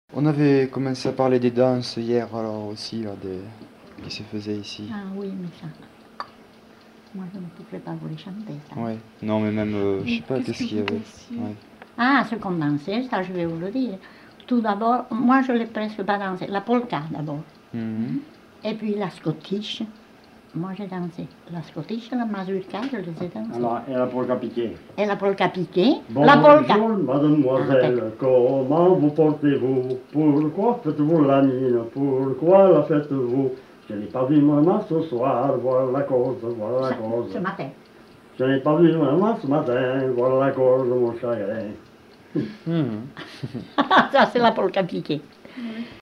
Lieu : Pause-de-Saut (lieu-dit)
Genre : chant
Effectif : 1
Type de voix : voix d'homme
Production du son : chanté
Danse : polka piquée